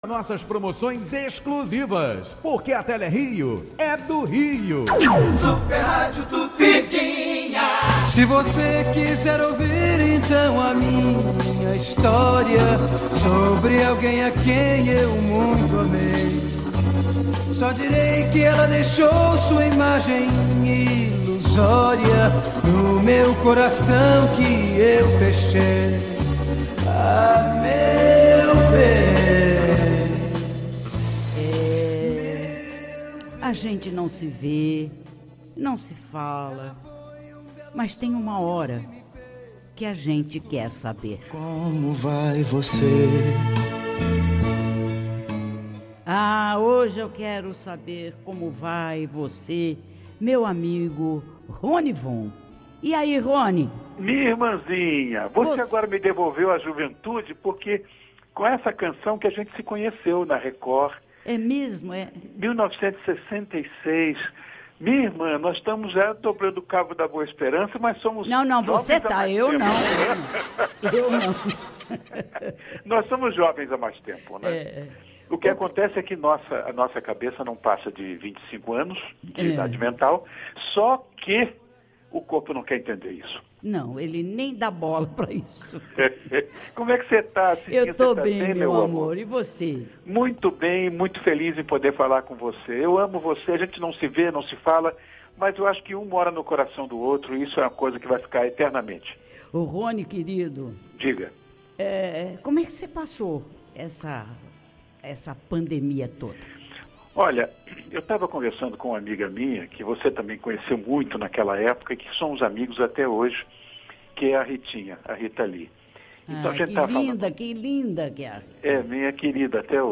[OUÇA] Ronnie Von e Cidinha Campos relembram amizade de décadas em bate-papo descontraído
Ronnie Von relembrou momentos marcantes em que passou ao lado de Cidinha Campos durante entrevista no quadro “Como Vai Você”.